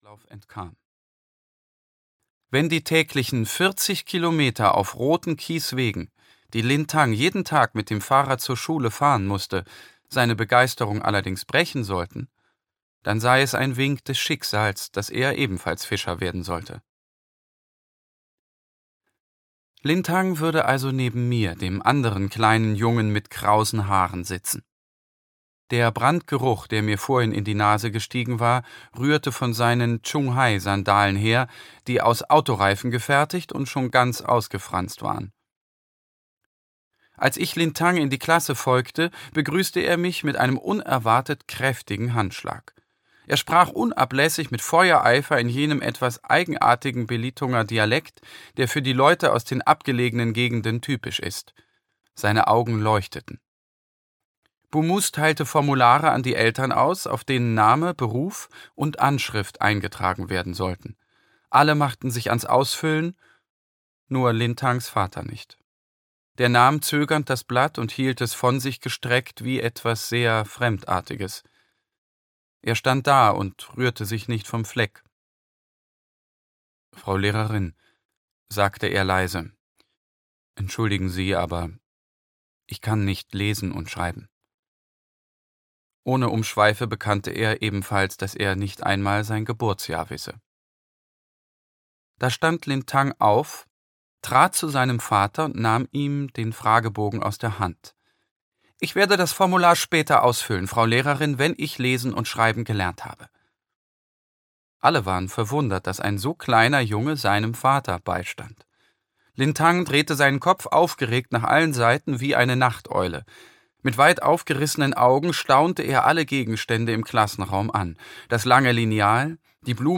Die Regenbogentruppe - Andrea Hirata - Hörbuch